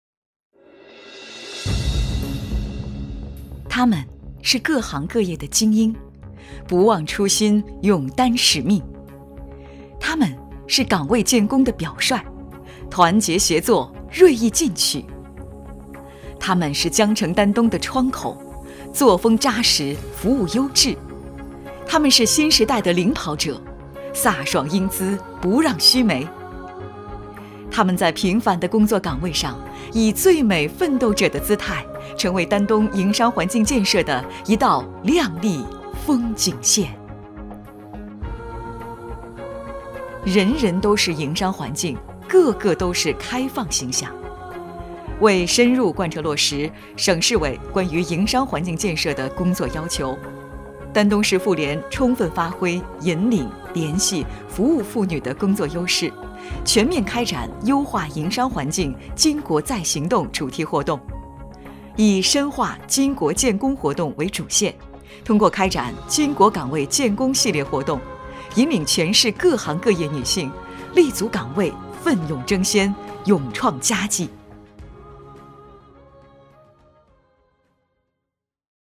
3 女国190_专题_政府_妇联宣传_稳重 女国190
女国190_专题_政府_妇联宣传_稳重.mp3